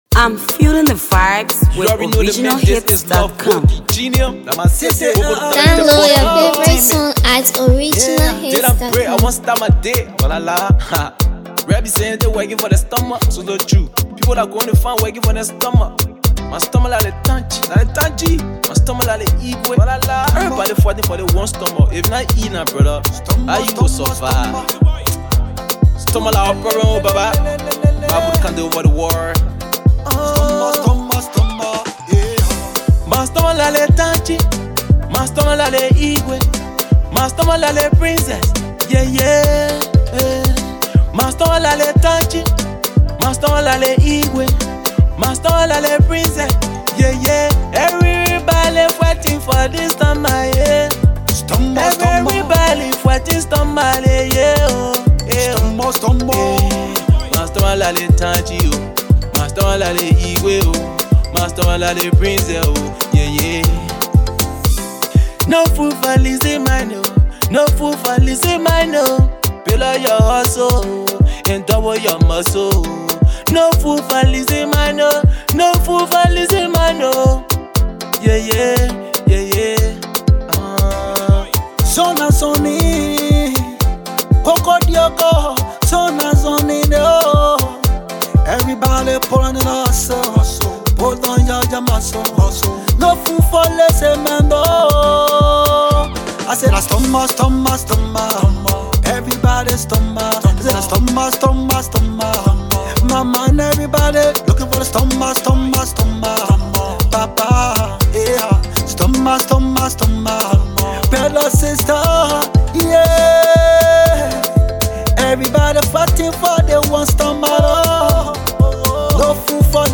studio jam